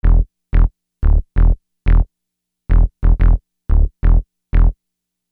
Hiphop music bass loops 4
Hiphop music bass loop - 90bpm 61